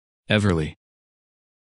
Написание и аудио произношение – Spelling and Audio Pronunciation